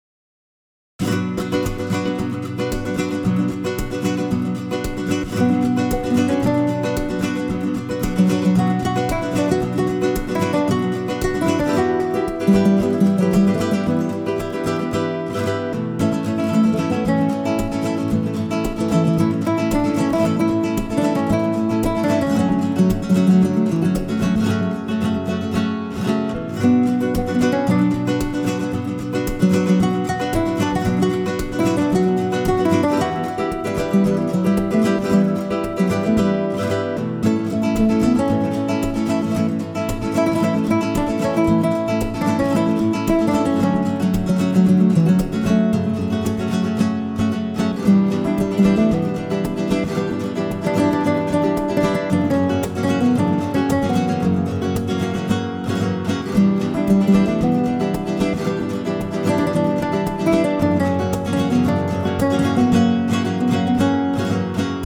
Demo with guitar midi solo